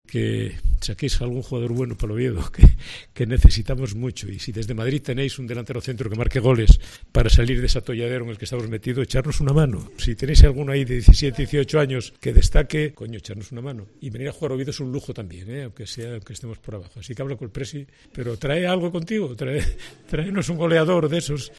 DECLARACIONES DEL ALCALDE
Alfredo Canteli, alcalde de Oviedo, compareció en un acto entre el Ayuntamiento de Oviedo y la Fundación Real Madrid.